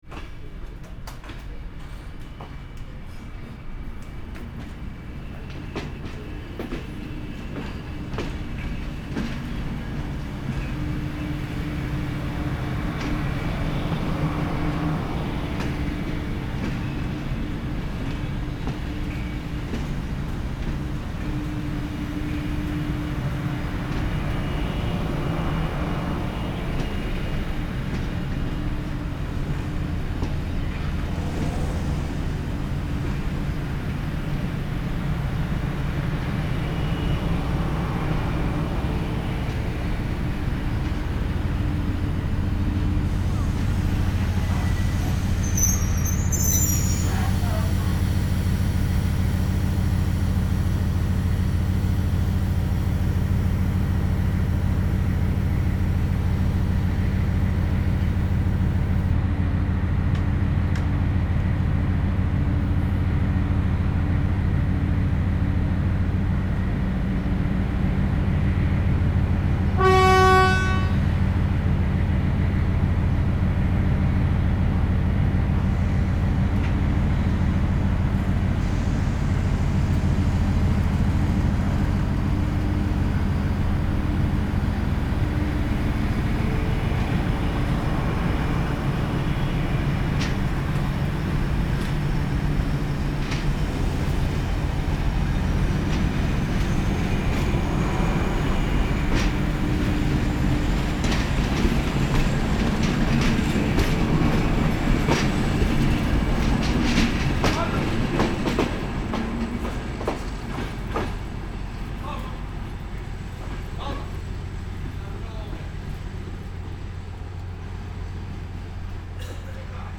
Train Reversing Moving Sound
transport
Train Reversing Moving